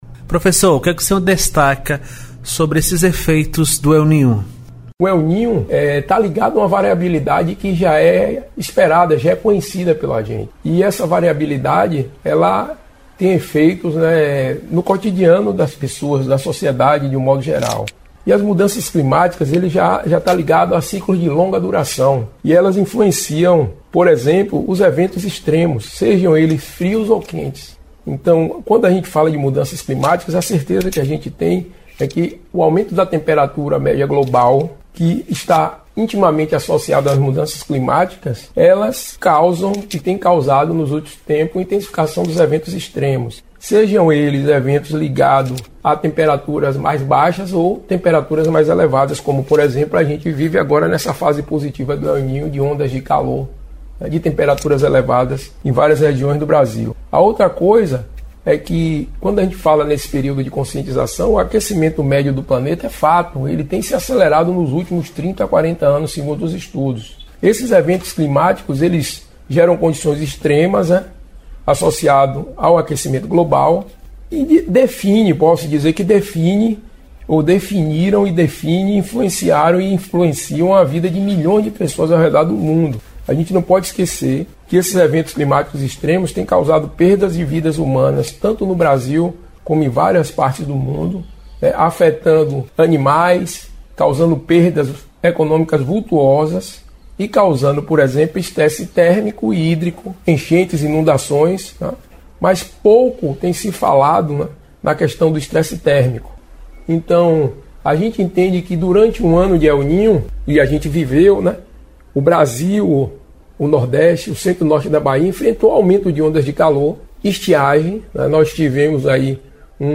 Geógrafo e professor